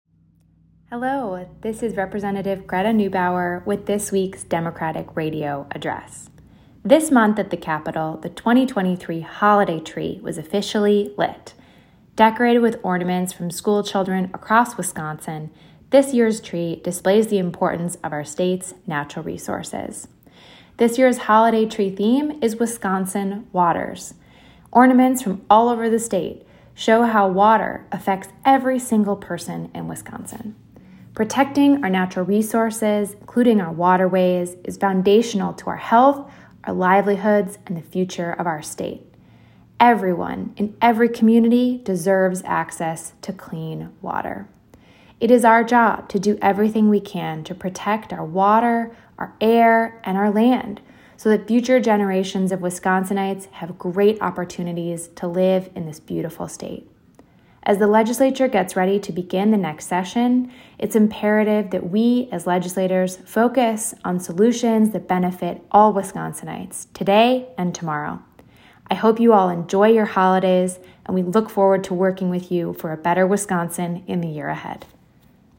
Weekly Democratic radio address: Rep. Greta Neubauer celebrates the lighting of the “Wisconsin Waters” themed 2023 capitol holiday tree and its significance - WisPolitics